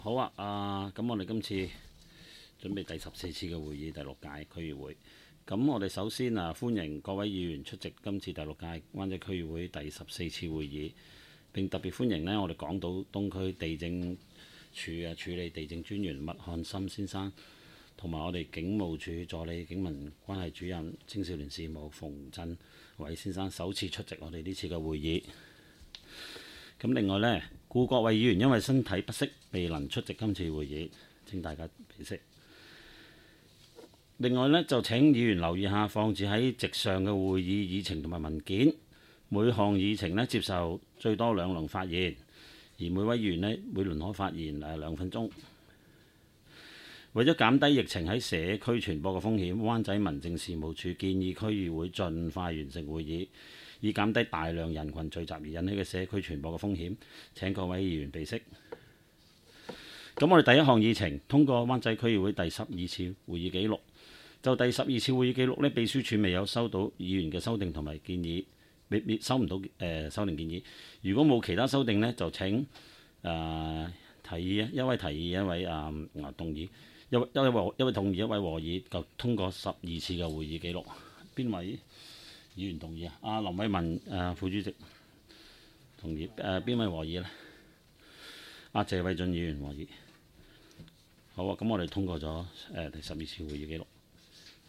区议会大会的录音记录
湾仔区议会第十四次会议
湾仔民政事务处区议会会议室